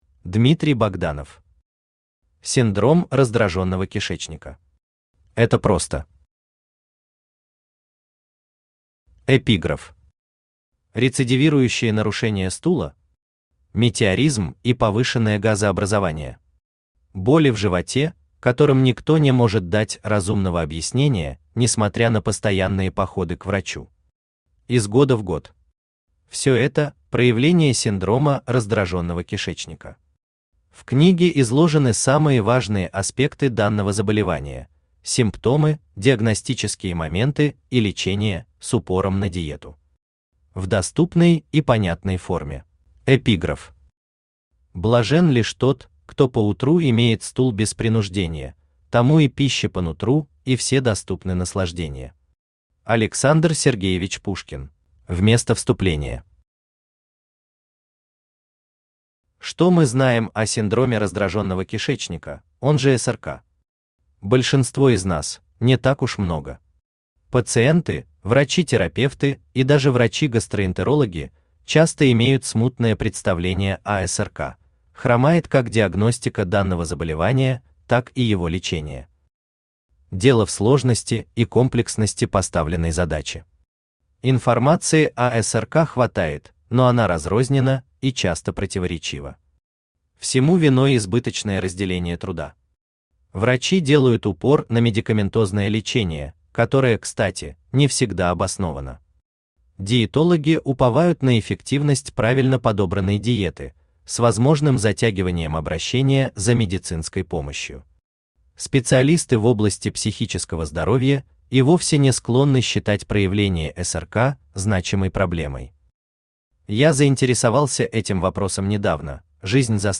Аудиокнига Синдром раздраженного кишечника. Это просто!
Автор Дмитрий Богданов Читает аудиокнигу Авточтец ЛитРес.